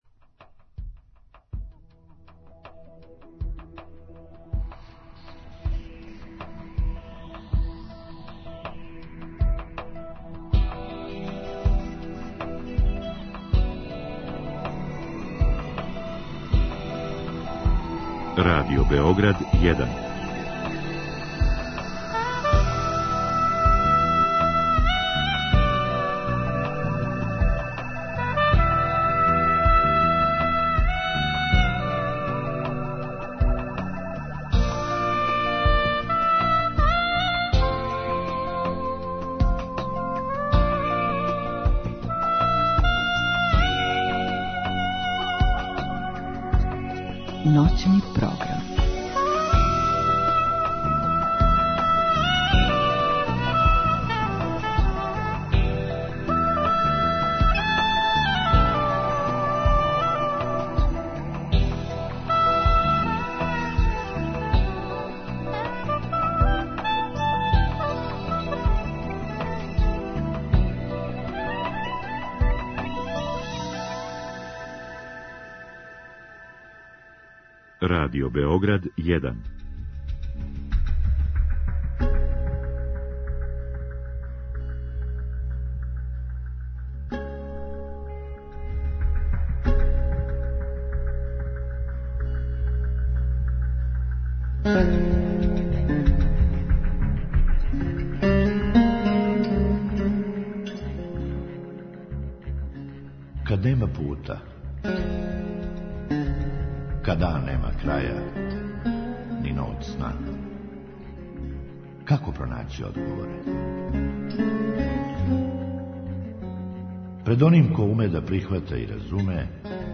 У другом сату ћемо причати са нашим слушаоцима о доношењу одлука, да ли им то тешко пада или не; да ли се са неким консултују и ко је тај „неко"; које су најтеже одлуке које су донели?